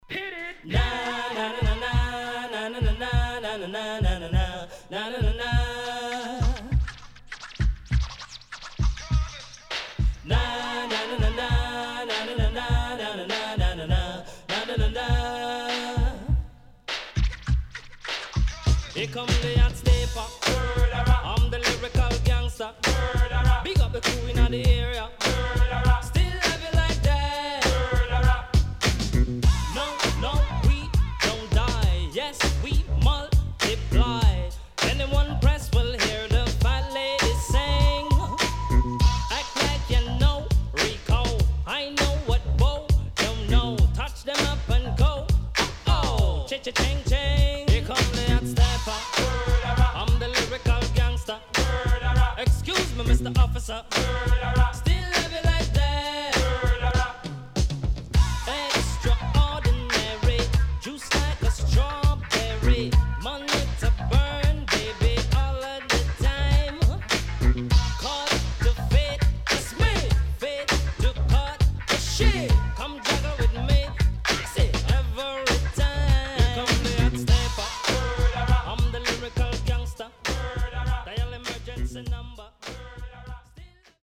HOME > LP [DANCEHALL]
SIDE A:少しチリノイズ入りますが良好です。